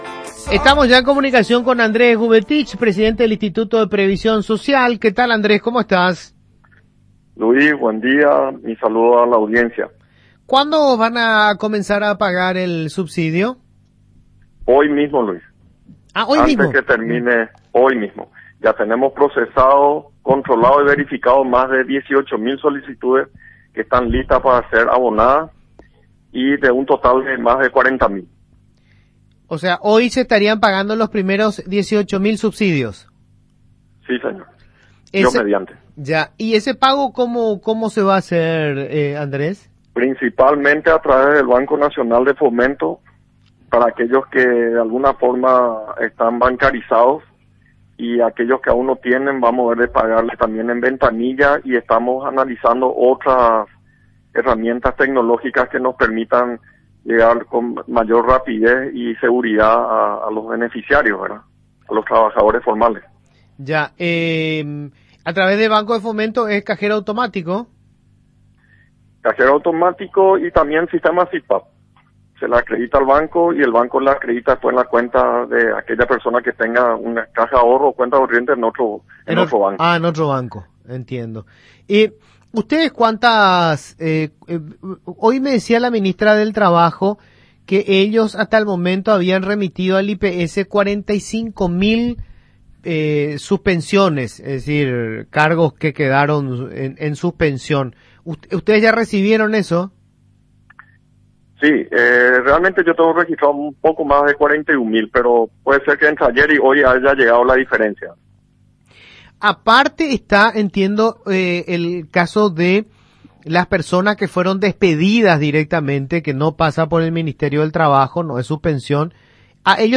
Andrés-Gubetich-presidente-del-IPS.mp3